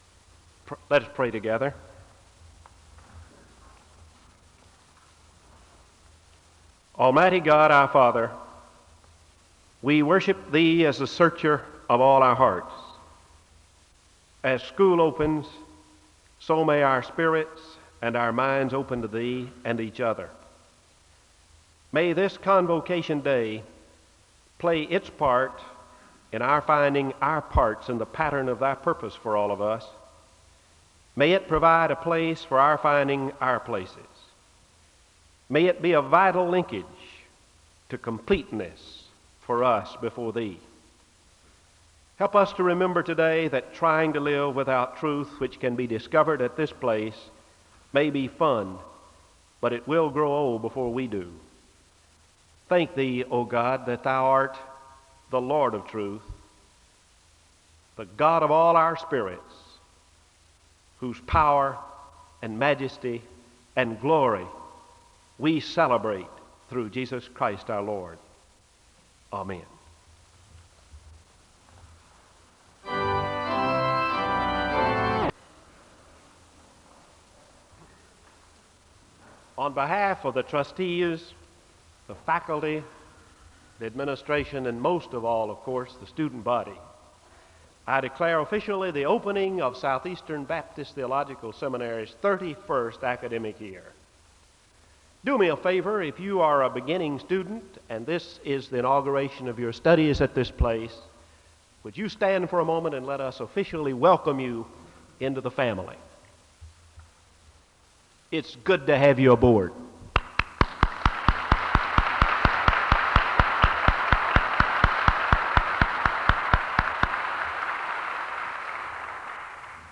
A woman sings a song of worship (10:57-16:45).
The service ends with a word of prayer (38:57-39:38).